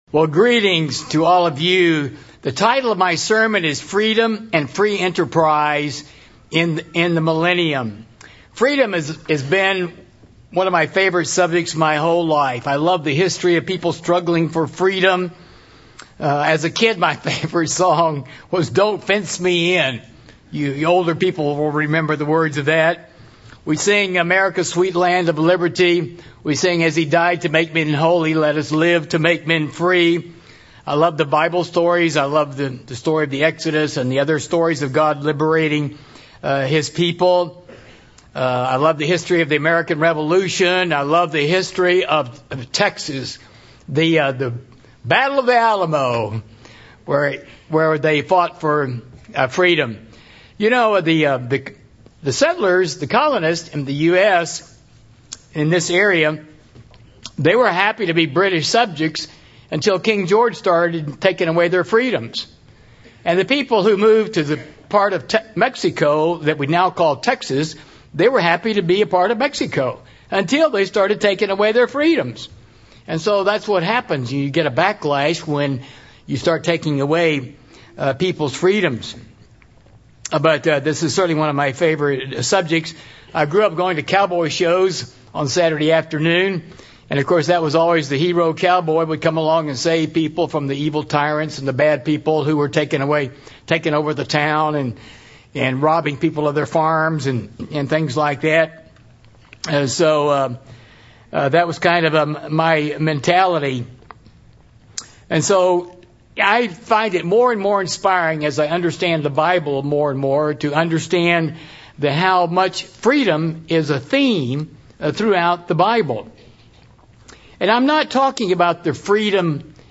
This sermon was given at the Branson, Missouri 2018 Feast site.